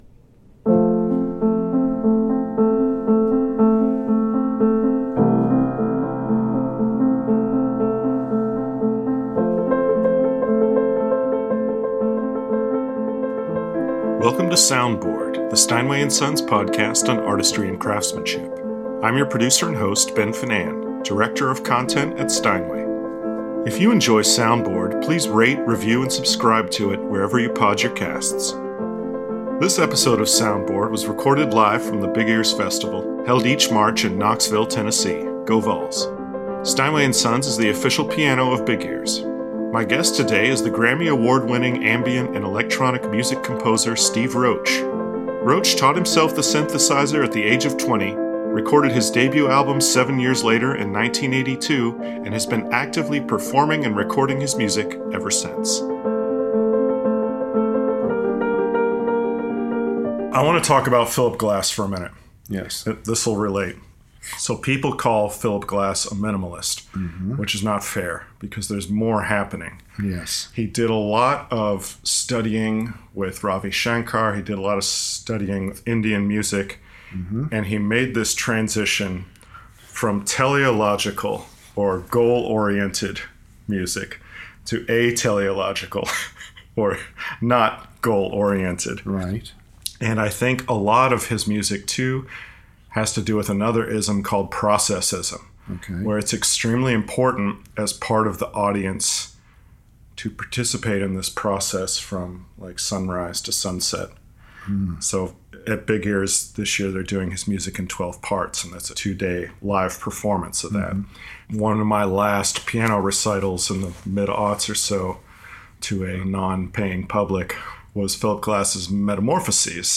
Latest was Sticky Jazz Interview The Bolshoi Brothers.